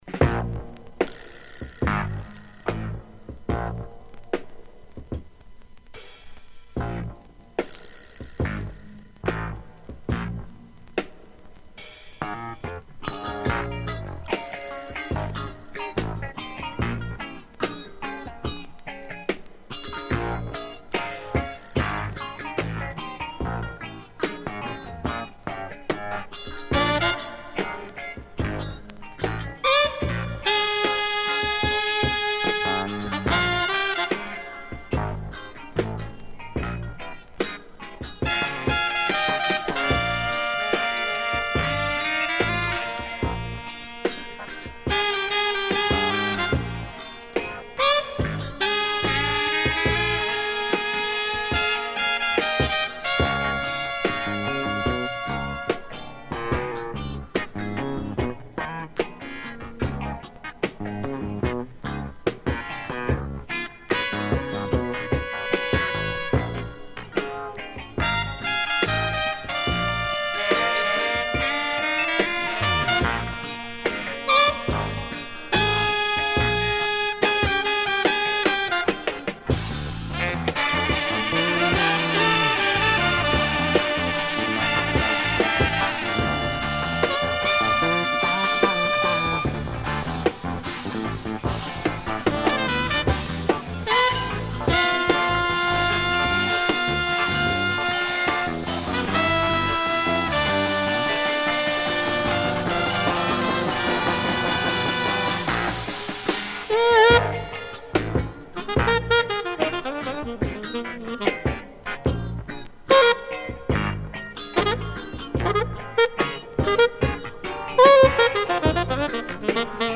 Killer groovy jazz